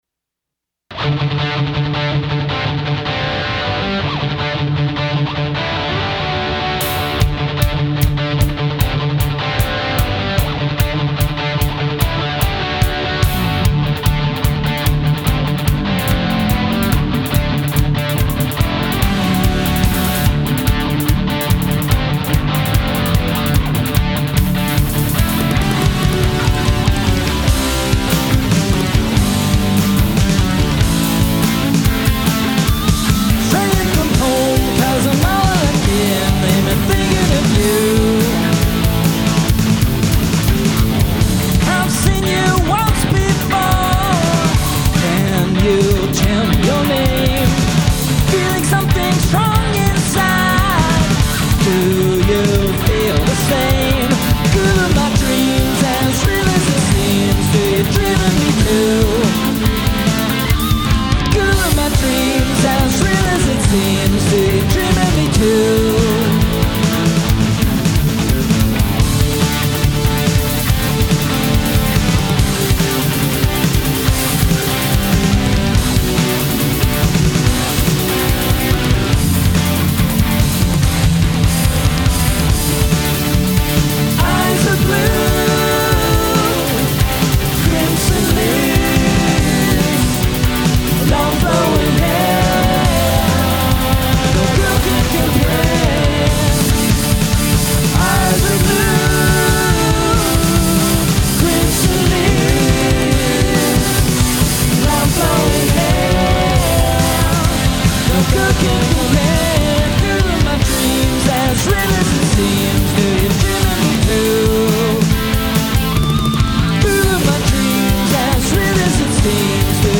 Here’s the original basement studio version: